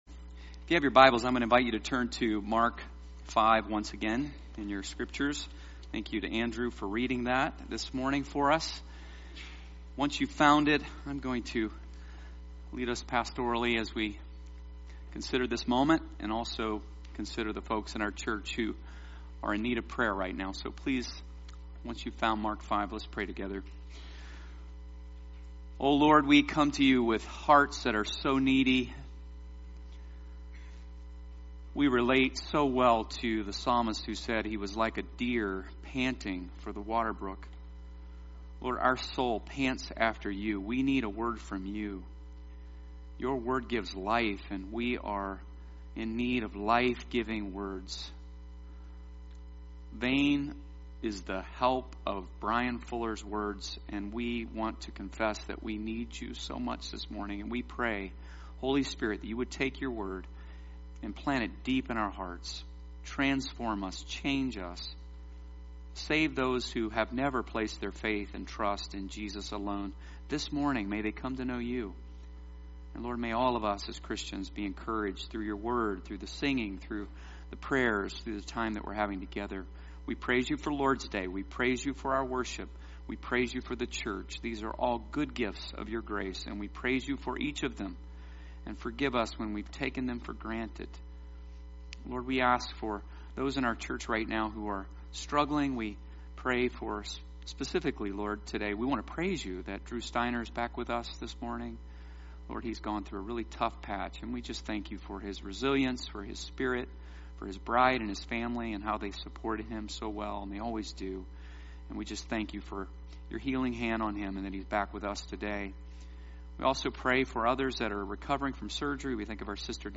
Sermon Series on the book of Mark